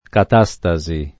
catastasi [ kat #S ta @ i ] s. f.